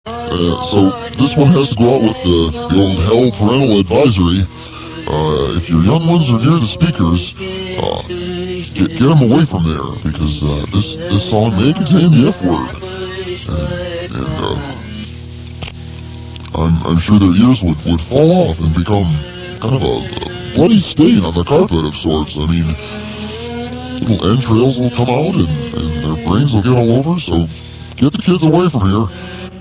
This show featured a DJ that had a voice changer that made him sound slowed down and deep, a little demonish, but like a friendly demon.